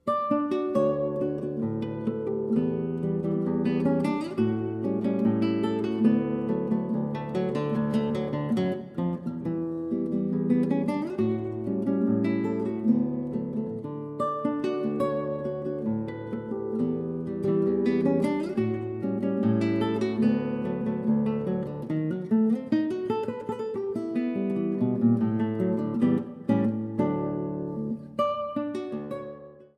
Solo Guitar